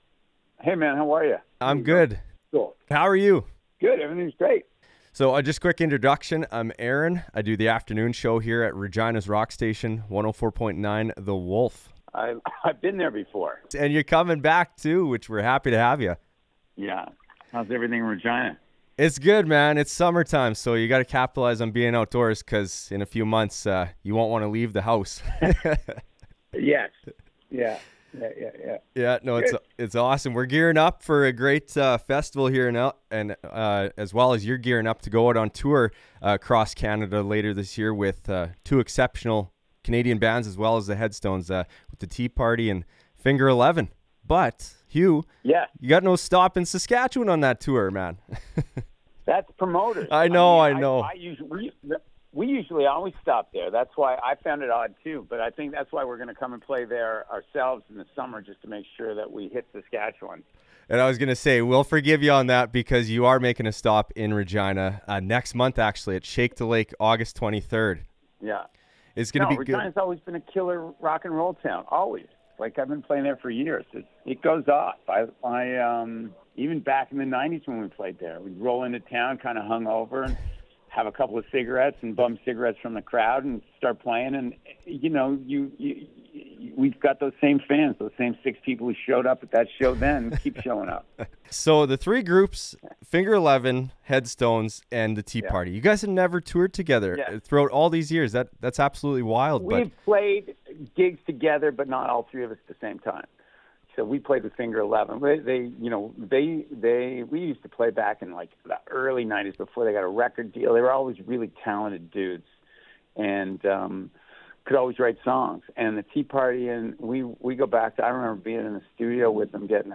This was a fun, unhinged and great interview with Hugh Dillon. I chatted with him about their upcoming Canadian tour with The Tea Party and Finger Eleven, their new album ‘Burn All The Ships’ which drops September 19th, collaborating with Dallas Green, and so much more. Our interview even gets interrupted by an unexpected guest.